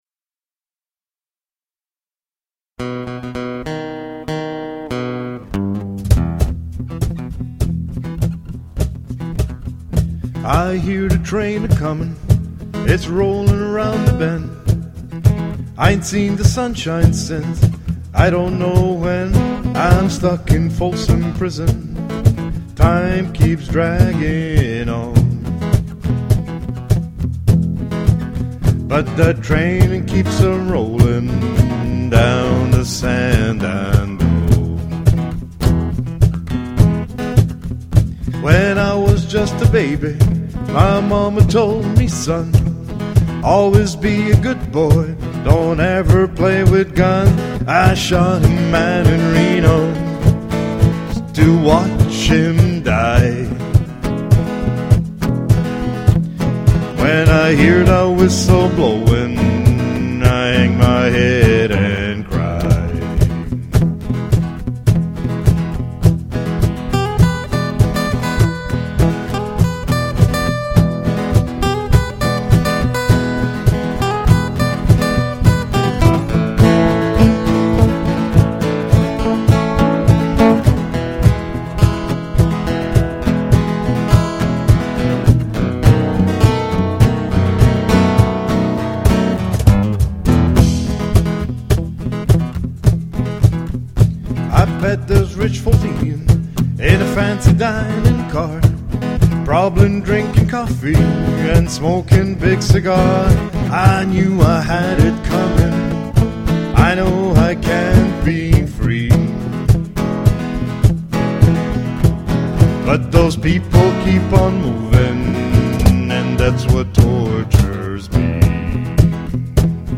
Cajon
Guitar